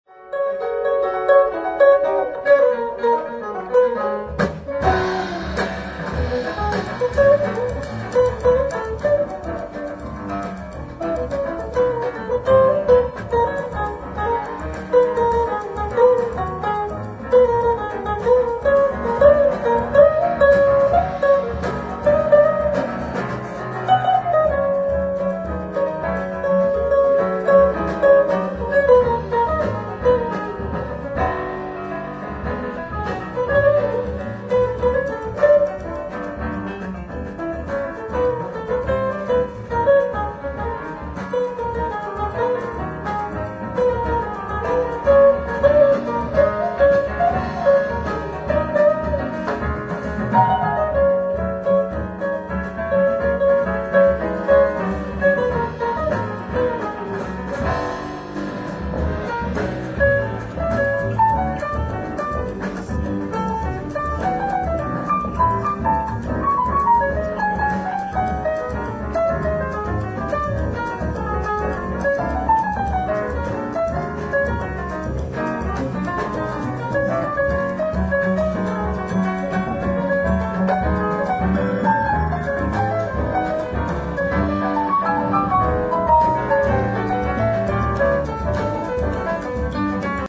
ライブ・アット・プレスコット・パーク、ポーツマス、ニューハンプシャー 08/05/2018
※試聴用に実際より音質を落としています。